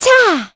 Gremory-Vox_Attack6.wav